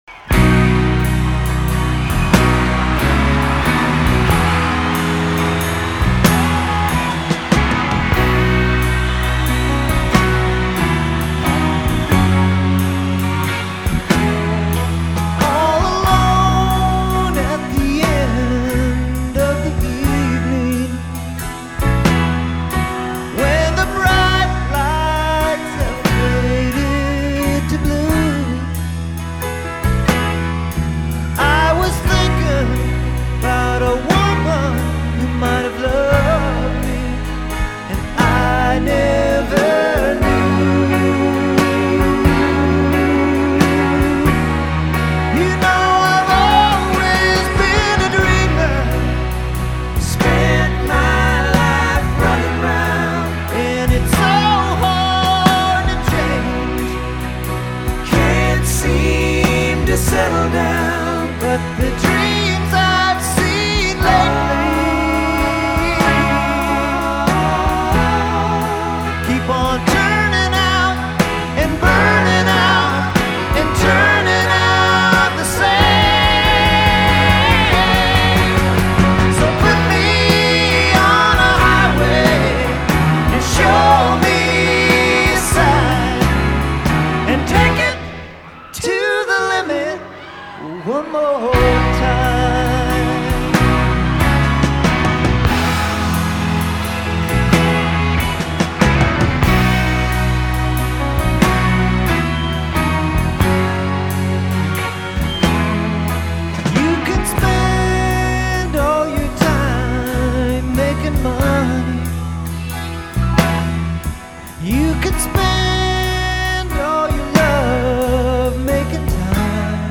Some are very quiet or have a lot of noise.
The Forum, Inglewood, CA
Orchestral accompaniment.